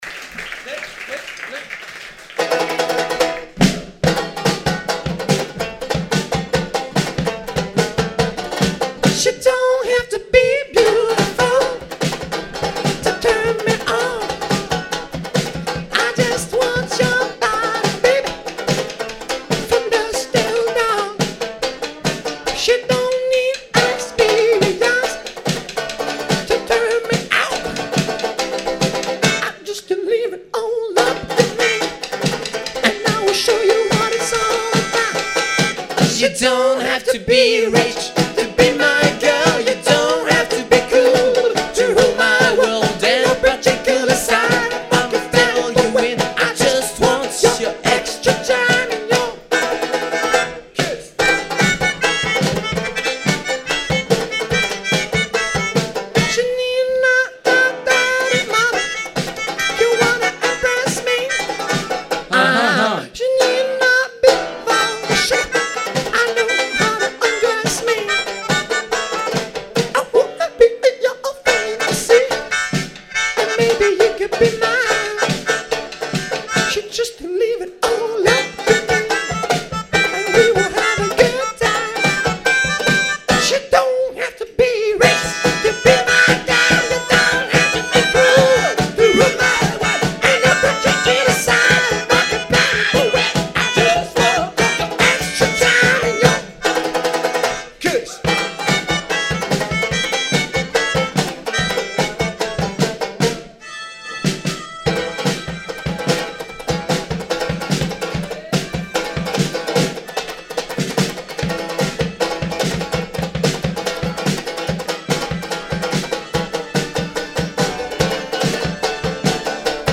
Live (07/05/05) avec des blagues super drôles